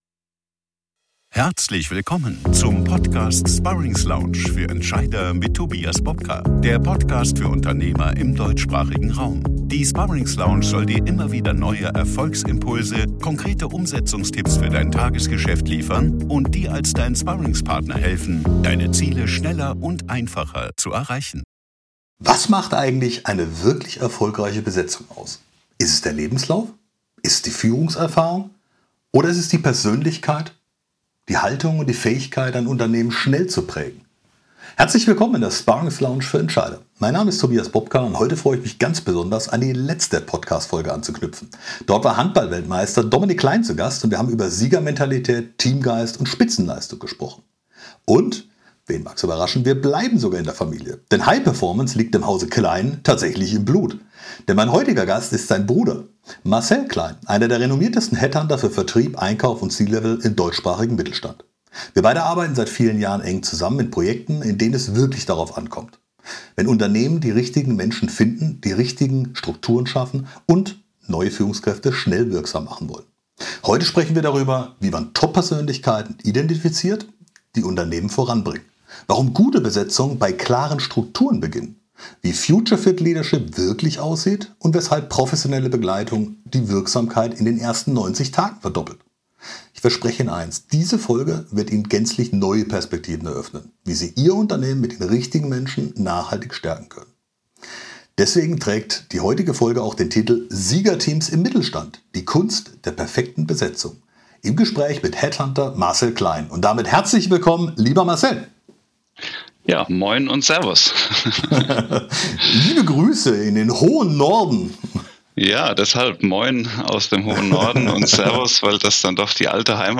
#56 Siegerteams im Mittelstand: Die Kunst der perfekten Besetzung – im Gespräch